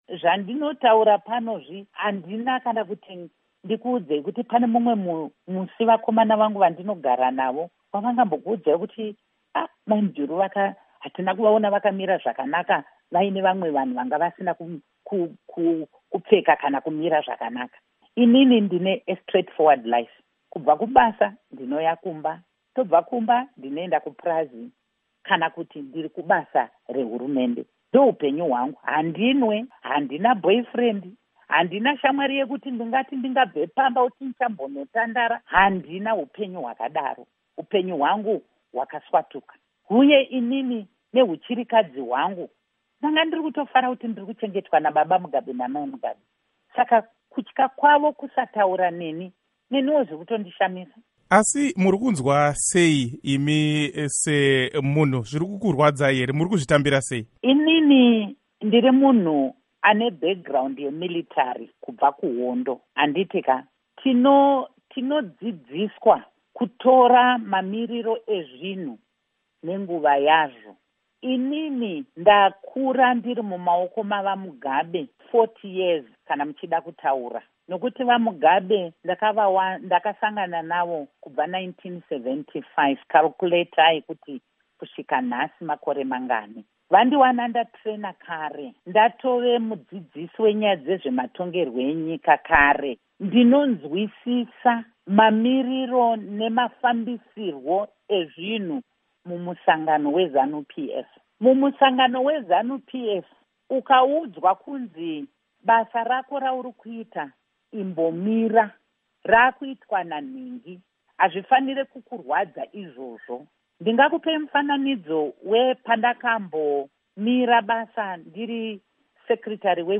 Hurukuro naAmai Joice Mujuru